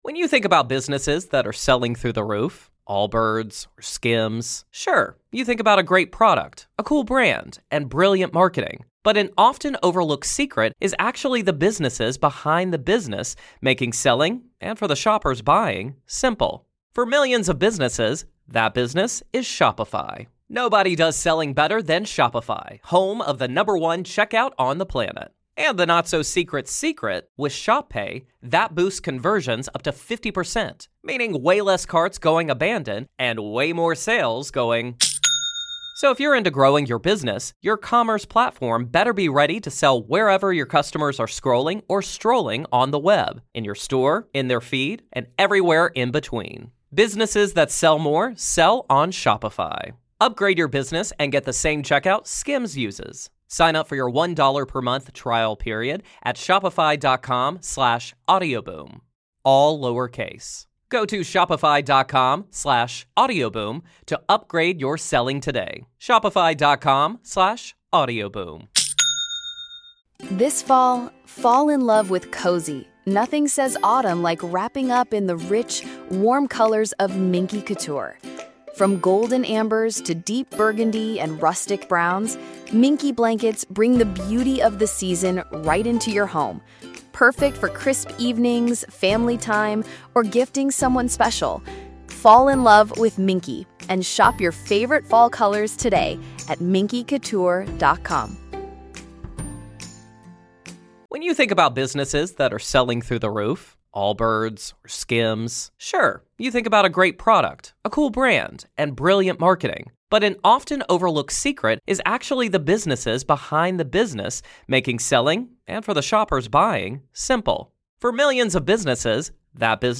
A truly wide-ranging conversation that connects human potential, intuition, and the mysteries of the cosmos.